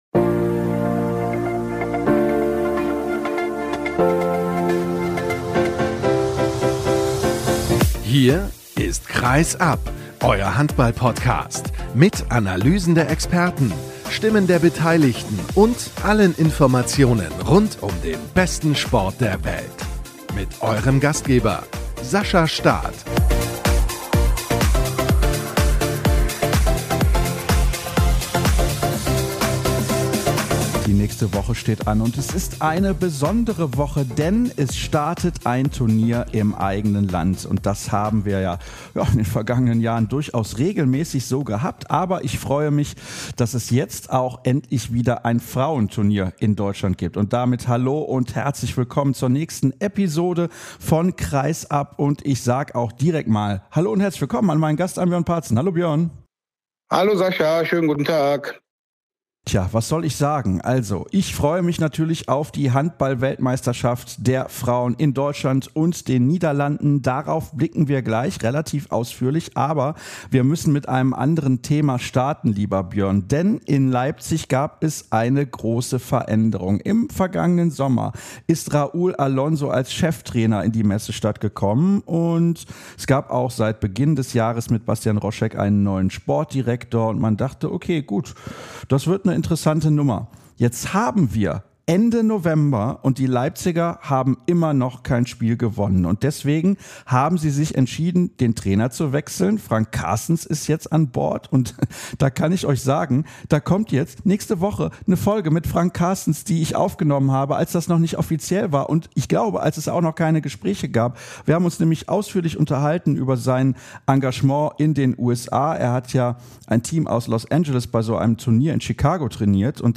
wie der ehemalige Erlanger im Interview der Woche ganz offen zugibt.